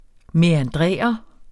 Udtale [ mεanˈdʁεˀʌ ]